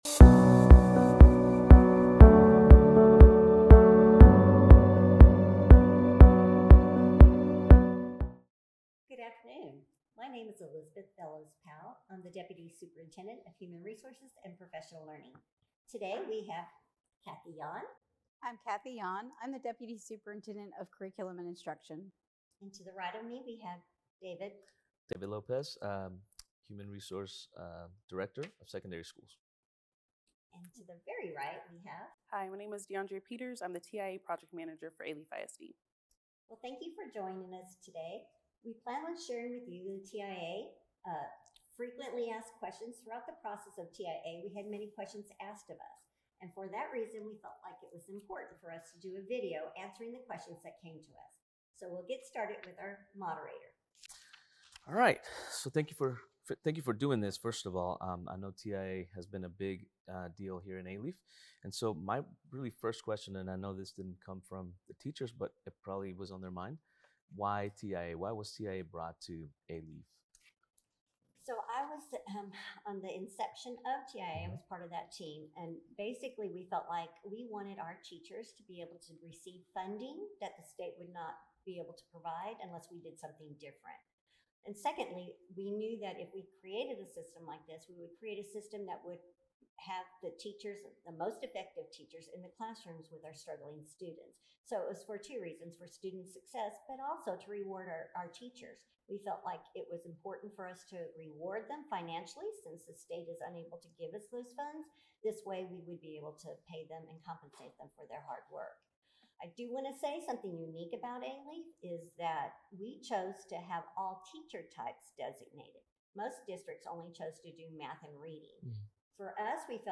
Teacher Incentive Allotment Q&A